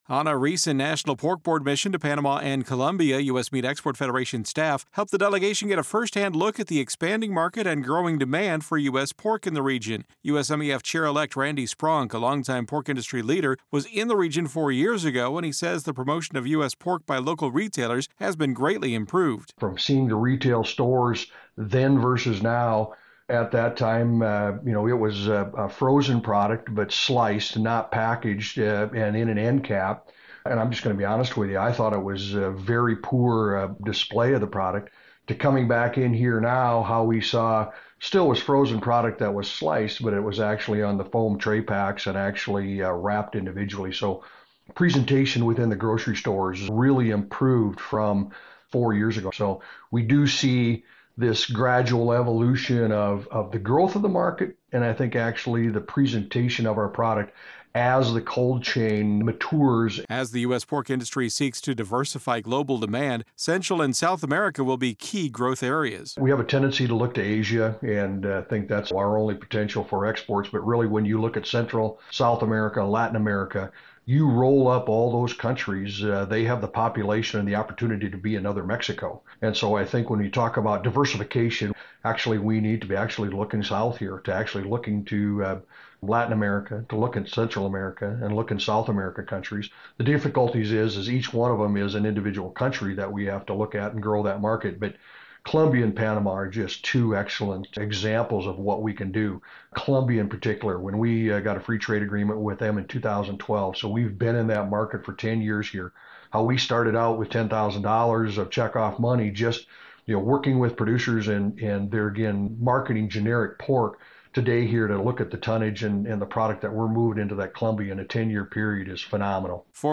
In this audio report